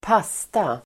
Uttal: [²p'as:ta]